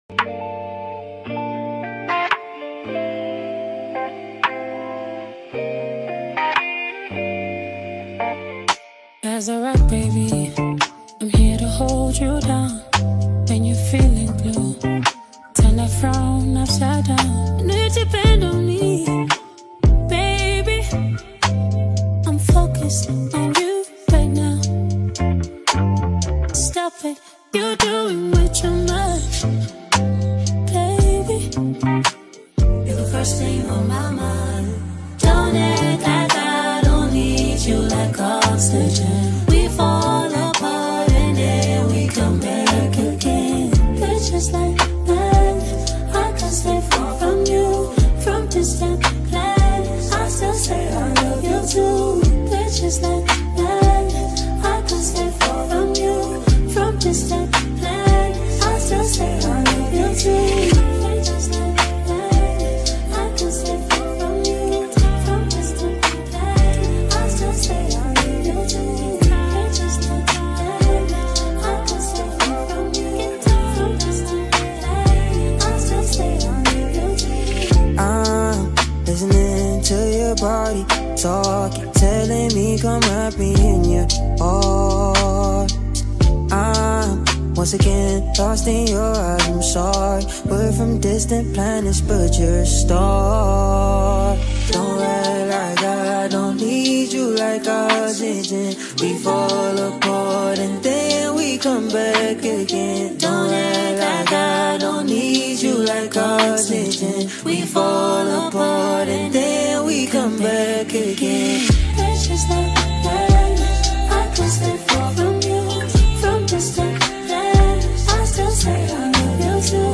Home » Amapiano » Lekompo
South African singer